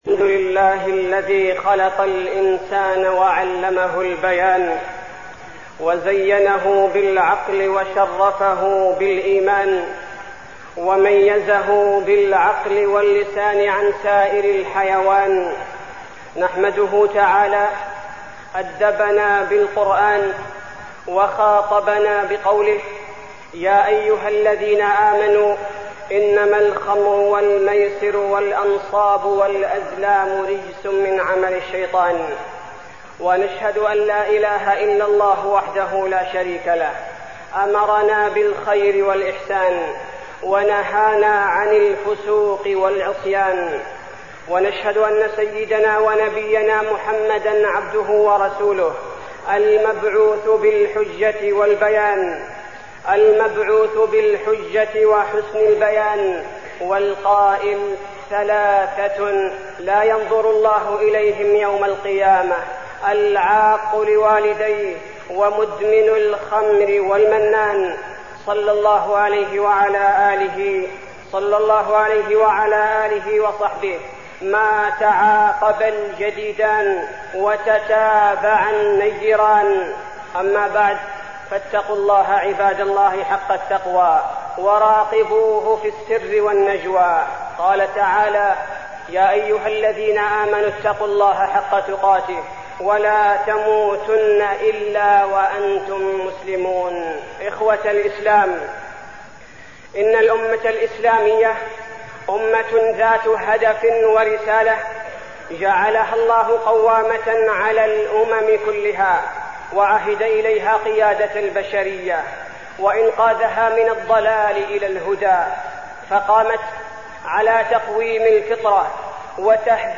تاريخ النشر ٢٧ جمادى الآخرة ١٤١٧ هـ المكان: المسجد النبوي الشيخ: فضيلة الشيخ عبدالباري الثبيتي فضيلة الشيخ عبدالباري الثبيتي آفة المخدرات وأضرارها